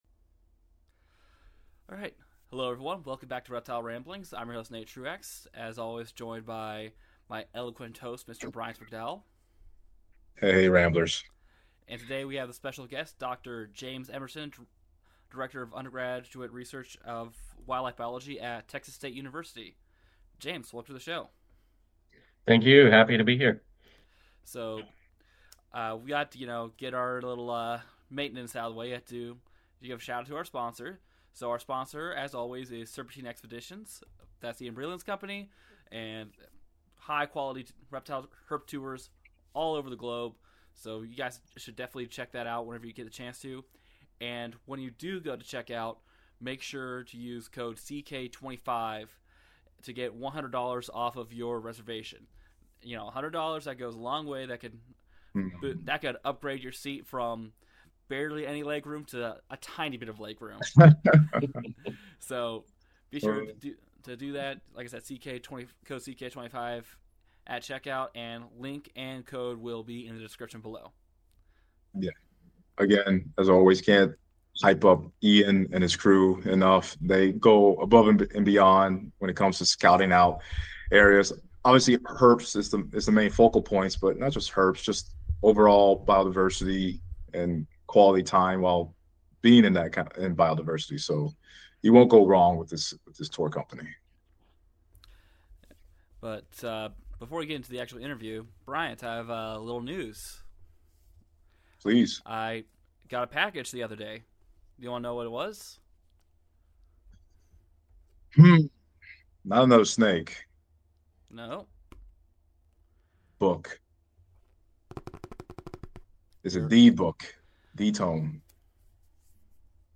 interview the leading figures in field herping, reptile research, zoo work and captive reptile husbandry